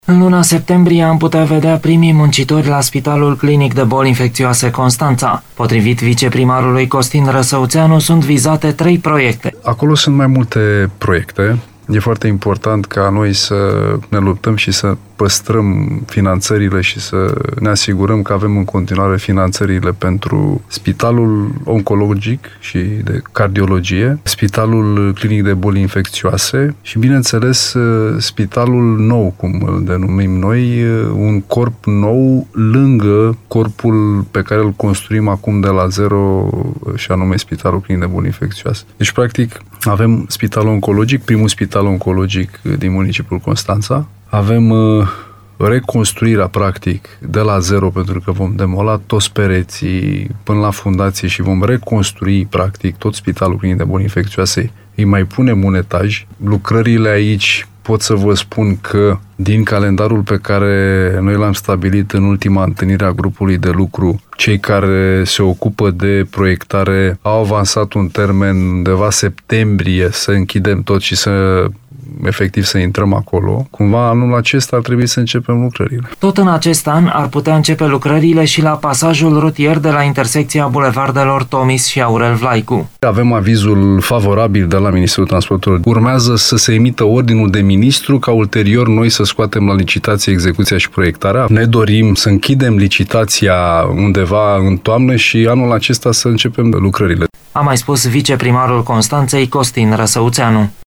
În acest an ar putea începe lucrările la viitorul pasaj rutier din cartierul Tomis Nord. Interviu cu viceprimarul Costin Răsăuțeanu